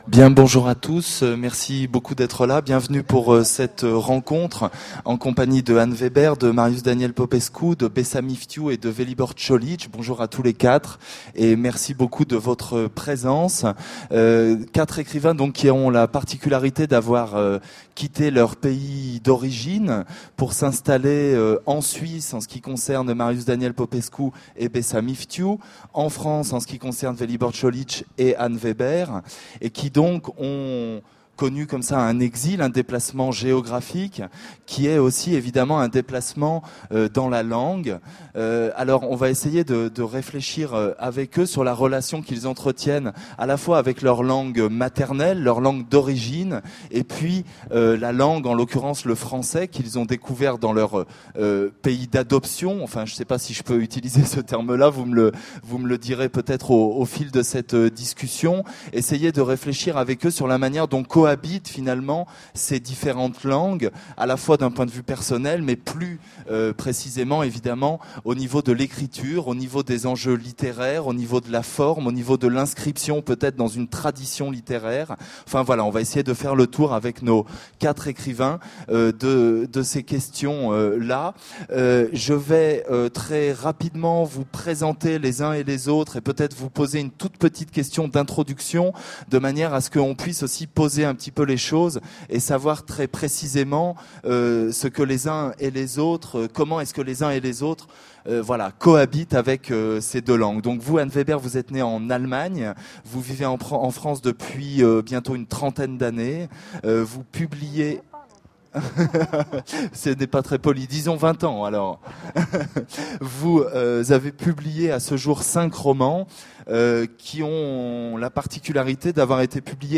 Le débat